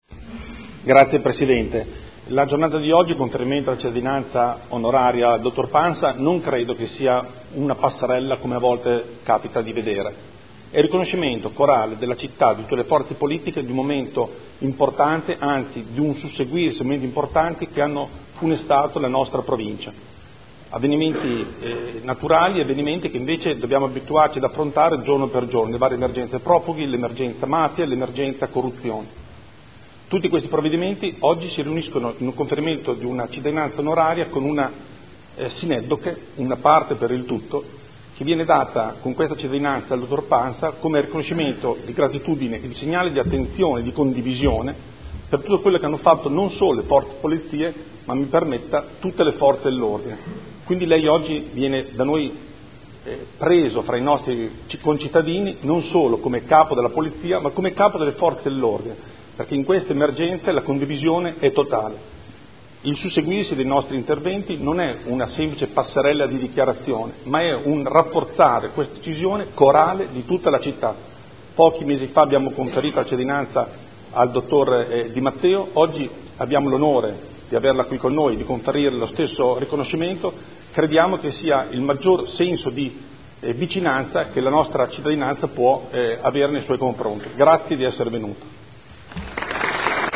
Seduta del 21/09/2015.